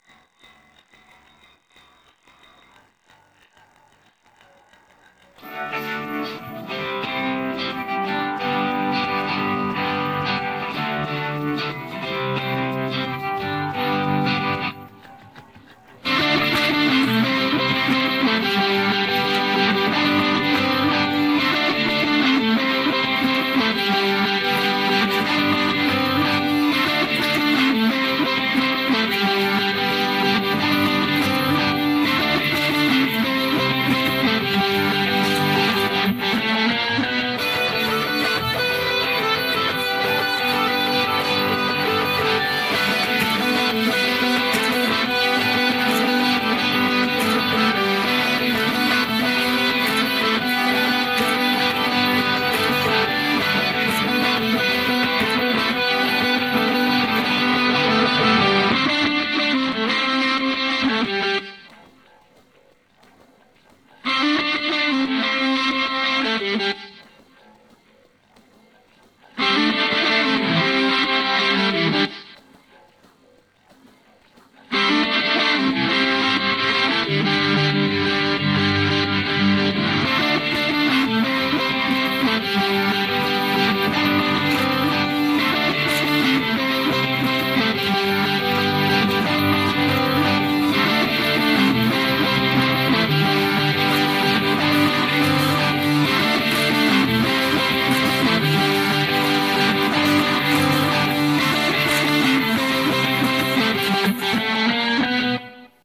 The other instruments are also silent for the first six seconds. The extraction is reasonably good, eliminating the bass and drums.
Other Instruments